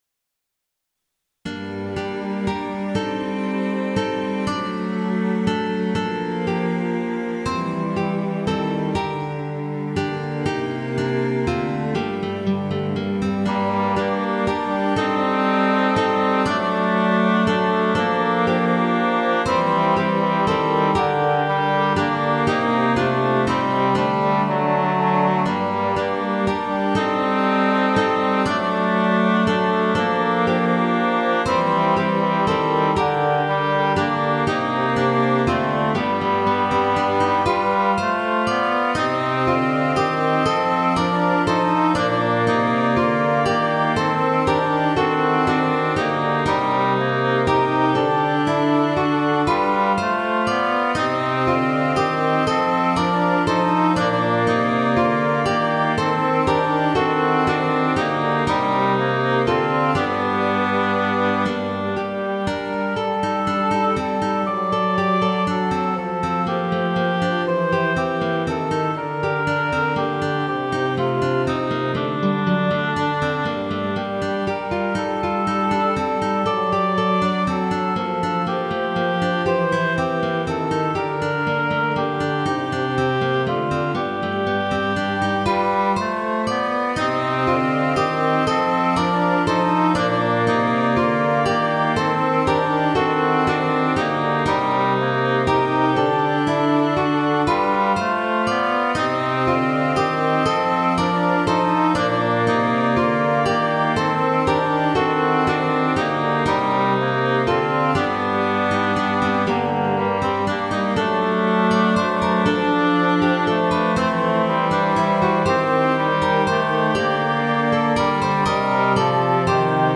▼DL↓   1.0 フルート オーボエ イングリッシュホルン ファゴット ギター